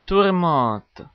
Ääntäminen
Synonyymit orage Ääntäminen France (Paris): IPA: /tuʁ.mɑ̃t/ Haettu sana löytyi näillä lähdekielillä: ranska Käännös Ääninäyte Substantiivit 1. storm US Suku: f .